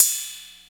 60 OP HAT 2.wav